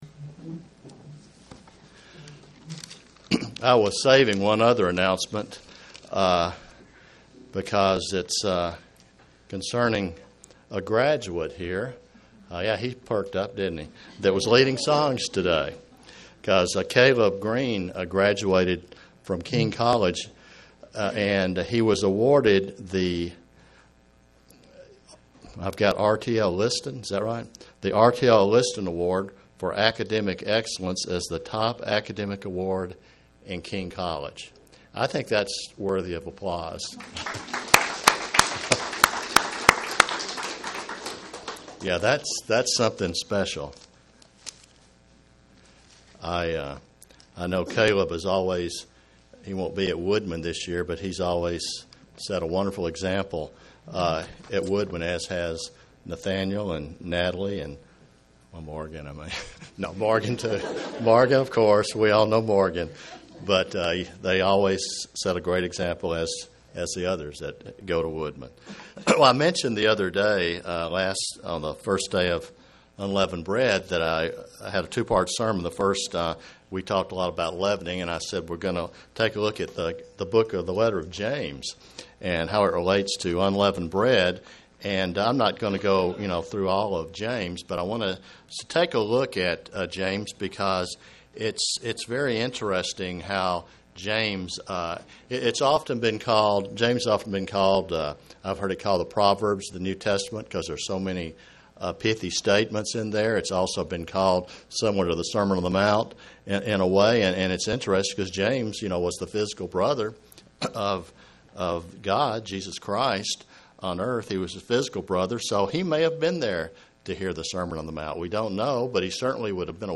The book of James is important in showing a Christian what God expects from each of us. Christianity is more than just saying one is a Christian but they must live a life worthy of the calling. (Presented to the Kingsport TN, Church)